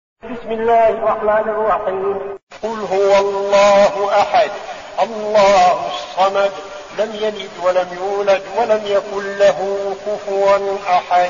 المكان: المسجد النبوي الشيخ: فضيلة الشيخ عبدالعزيز بن صالح فضيلة الشيخ عبدالعزيز بن صالح الإخلاص